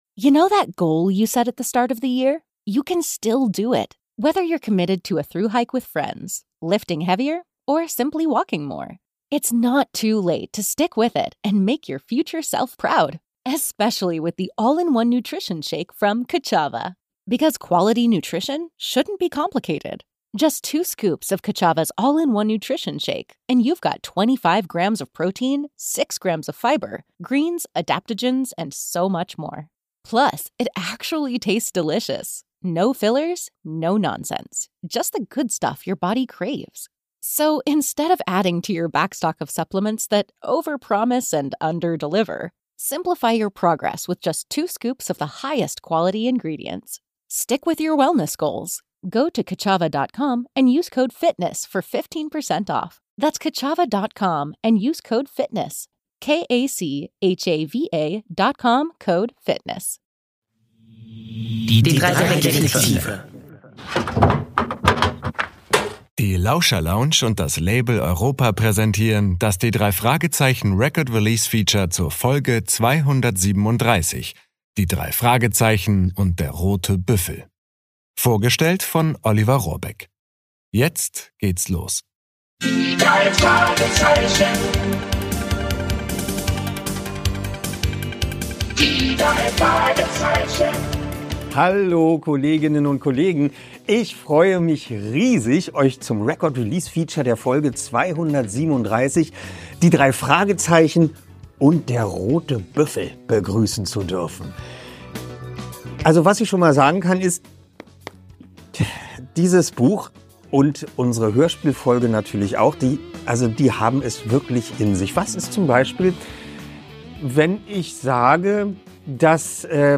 Sprecher: Oliver Rohrbeck